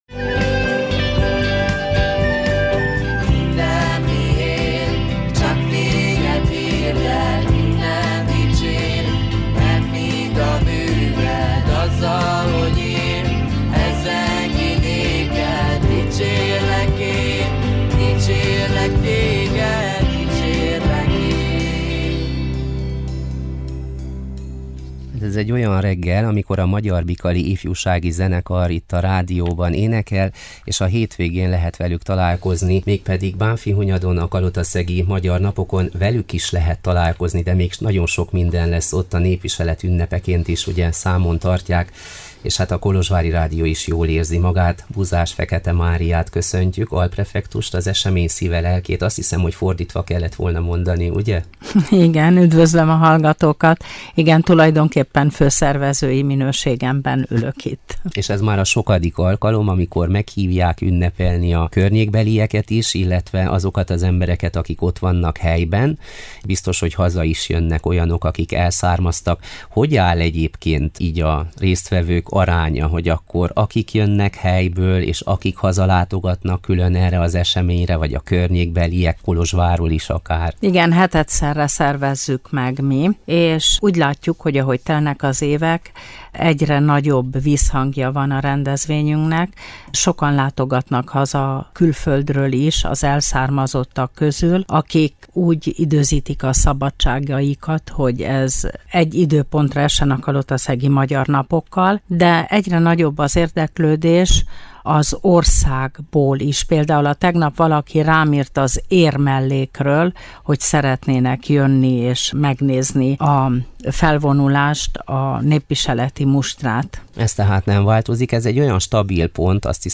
Az esemény főszervezője, Buzás-Fekete Mária Kolozs megyei alprefektus volt a Hangoló vendége.